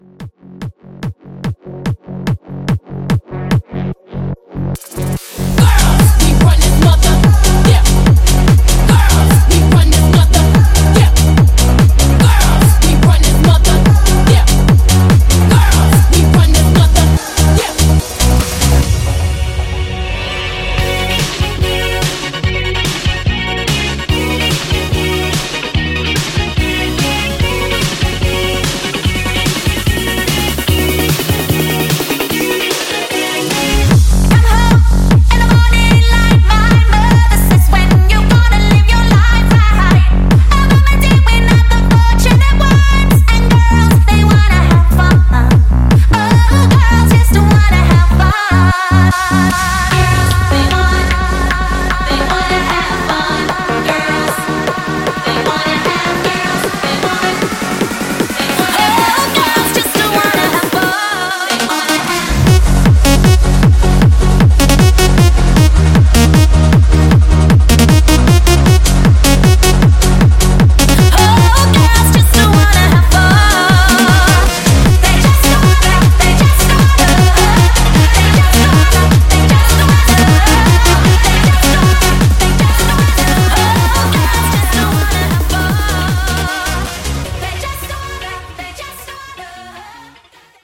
Genre: 80's
Clean BPM: 128 Time